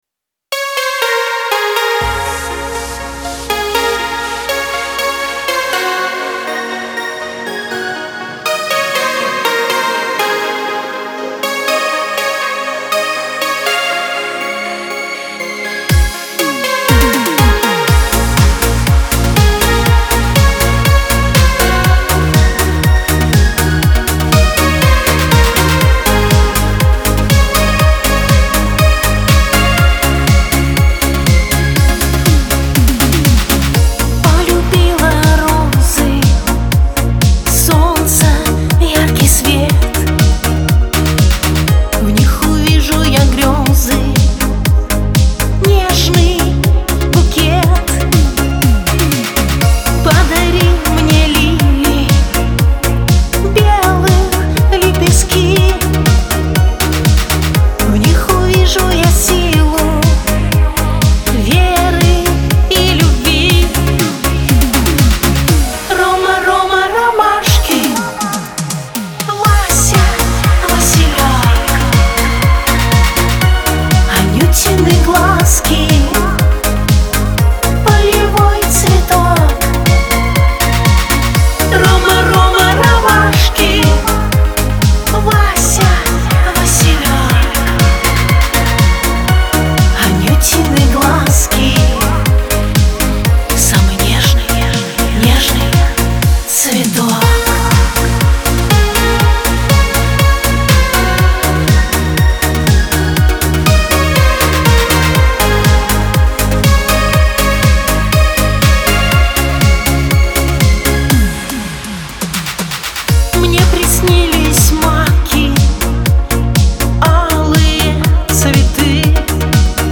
диско
pop